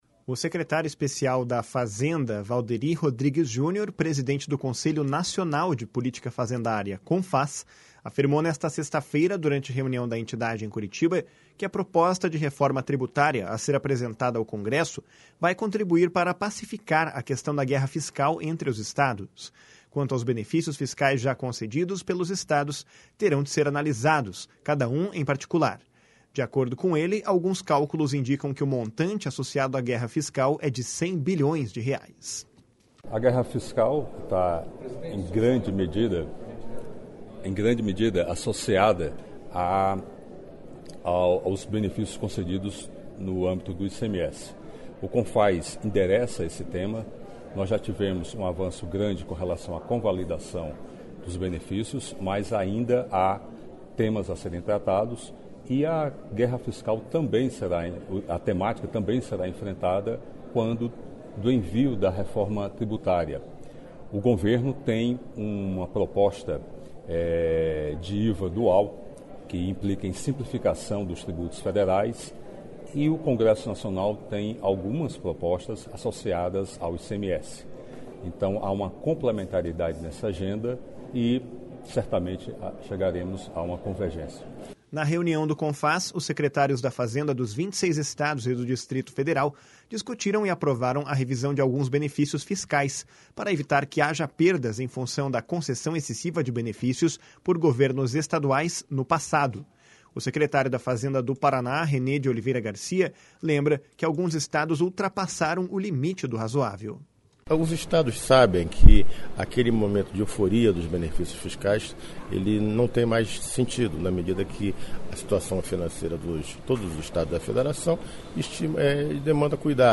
De acordo com ele, alguns cálculos indicam que o montante associado à guerra fiscal é de 100 bilhões de reais. // SONORA WALDERY RODRIGUES //
// SONORA RENÊ GARCIA //